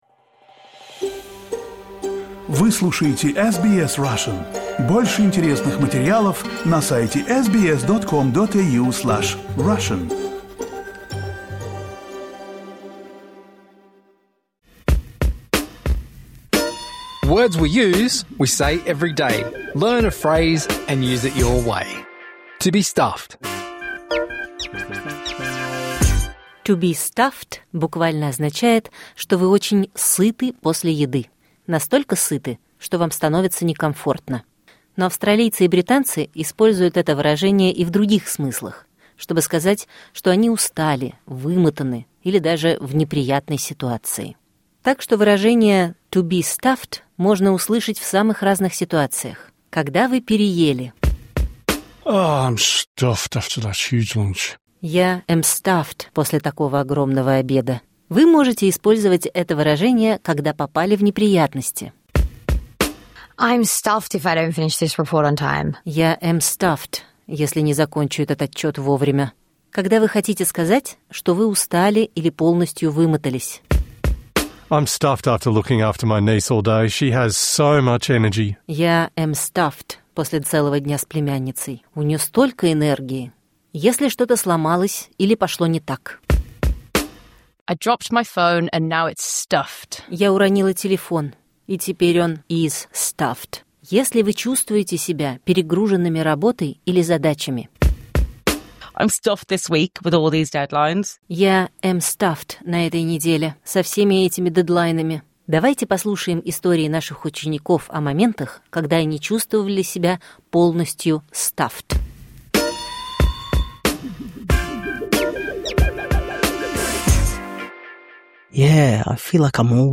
Words We Use — это двуязычная серия, которая помогает понять такие идиомы, как, например, «to be stuffed».